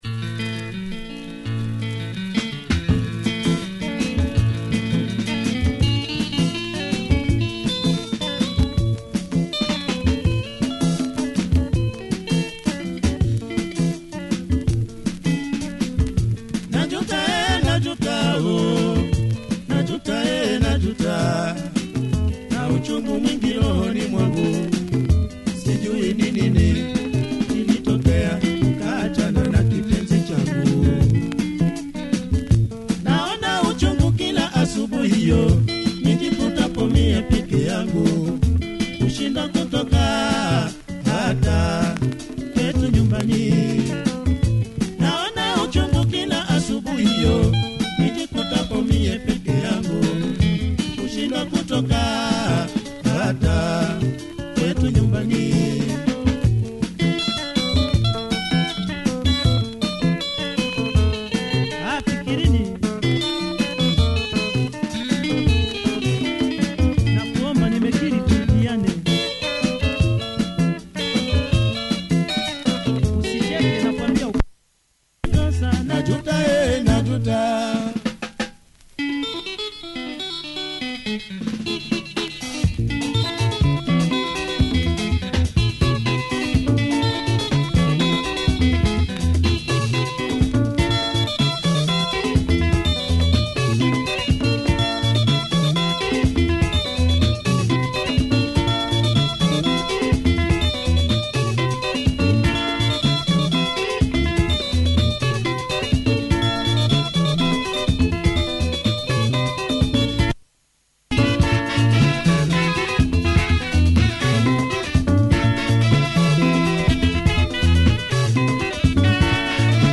anyways good production, changes pace mid-way